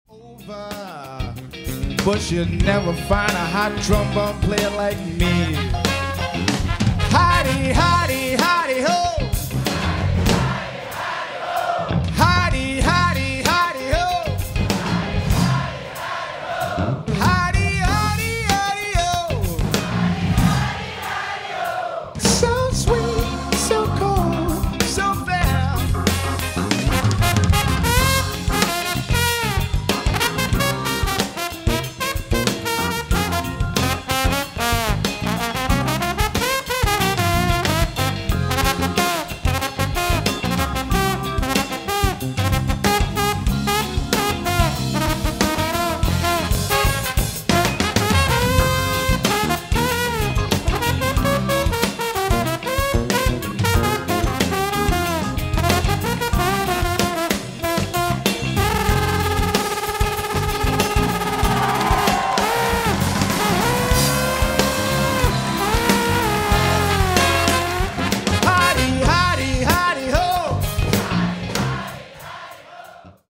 Trombone Short plays for the sound effects free download